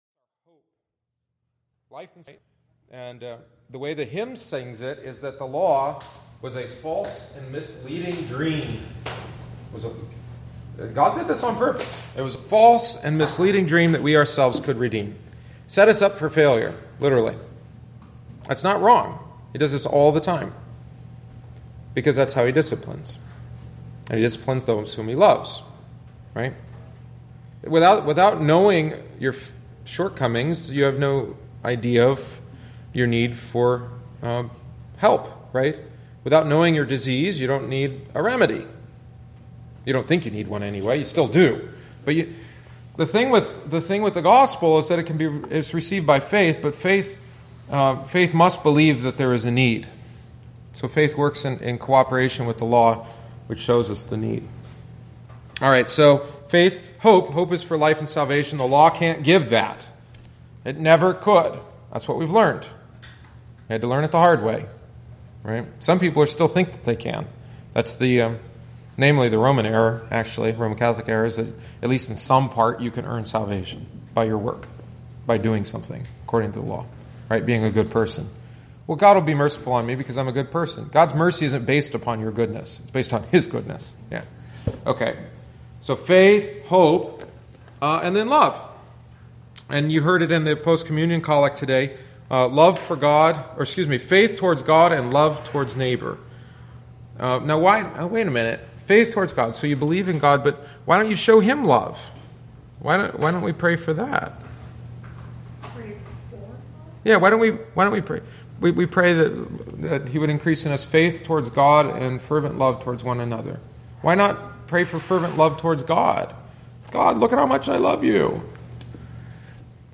Join us for Adult Catechumenate classes following the each Wednesday Divine Service. This is offered for those that would like a refresher course on their catechetical instruction and especially for those desiring to join us and confess the Lutheran faith.